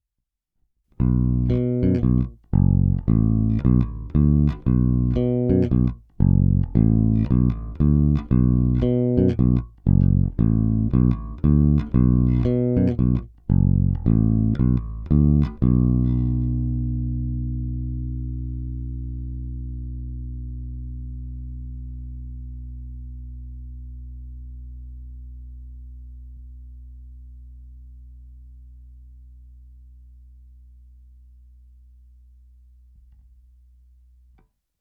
Není-li uvedeno jinak, nahrávky jsou provedeny rovnou do zvukové karty, bez stažené tónové clony a bez použití korekcí.
Hráno nad použitým snímačem, v případě obou hráno mezi nimi.
Oba snímače